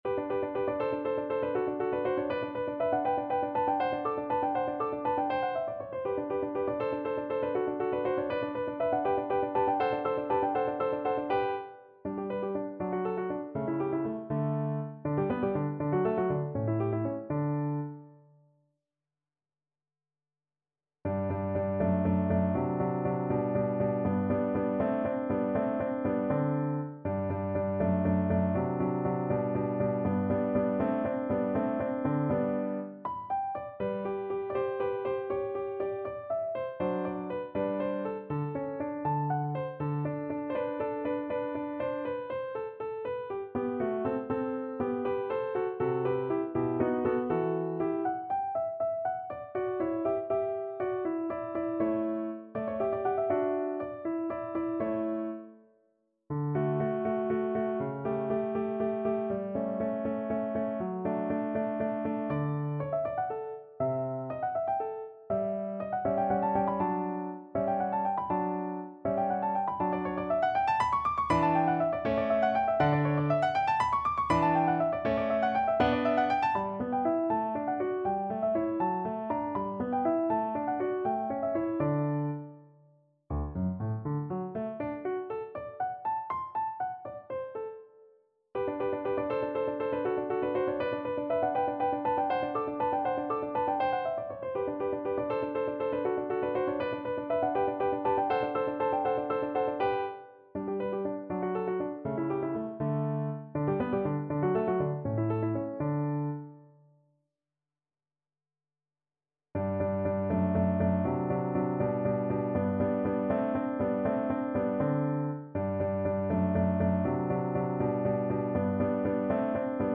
Play (or use space bar on your keyboard) Pause Music Playalong - Piano Accompaniment Playalong Band Accompaniment not yet available transpose reset tempo print settings full screen
6/8 (View more 6/8 Music)
G major (Sounding Pitch) (View more G major Music for Oboe )
~ = 100 Allegro (View more music marked Allegro)
Classical (View more Classical Oboe Music)